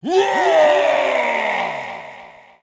Barbian King Rage Yell Clash Of Clans